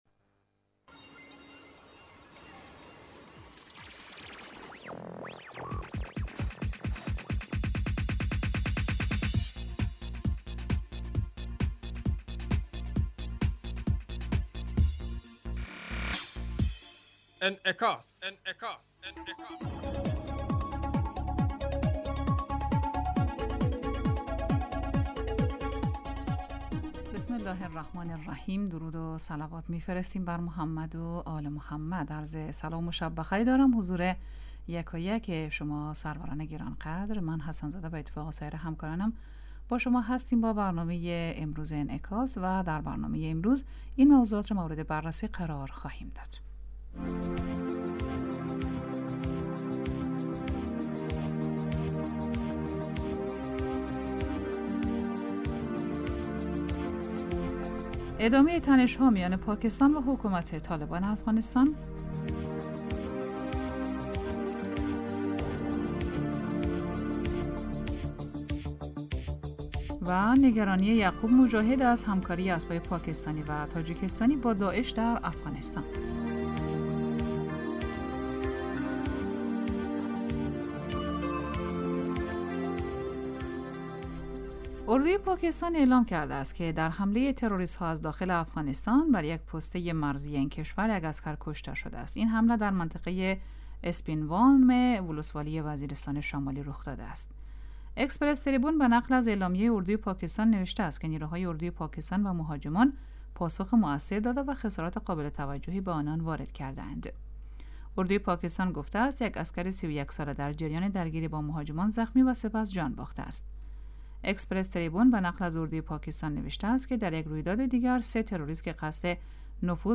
برنامه انعکاس به مدت 35 دقیقه هر روز در ساعت 06:50 بعد از ظهر (به وقت افغانستان) بصورت زنده پخش می شود. این برنامه به انعکاس رویدادهای سیاسی، فرهنگی، اقتصادی و اجتماعی مربوط به افغانستان، برخی از خبرهای مهم جهان و تحلیل این رویدادها می پردازد.